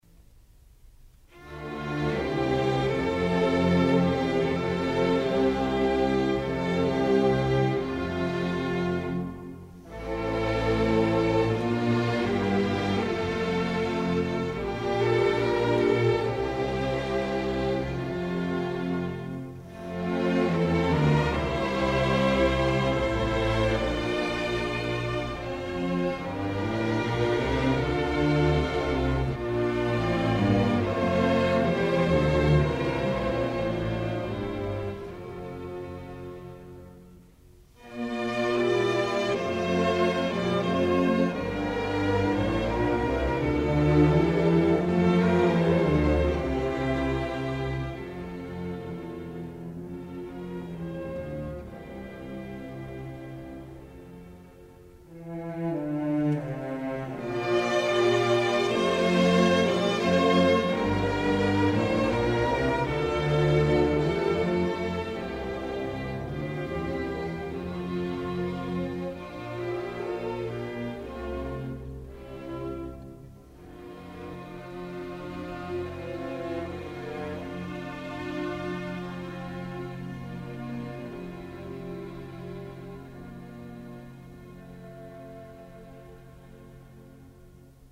ce noble  air de cour :